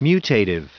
Prononciation du mot mutative en anglais (fichier audio)
Prononciation du mot : mutative